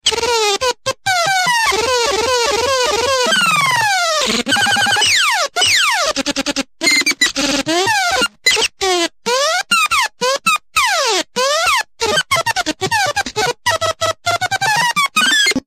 crazy-computer_25033.mp3